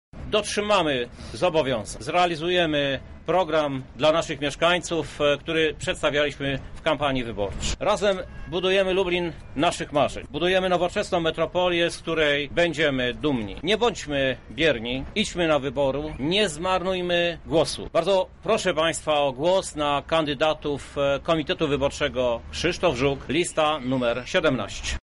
W trakcie konferencji pod Zamkiem Lubelskim do mieszkańców i swojego komitetu zwrócił się prezydent Krzysztof Żuk.
briefing zuk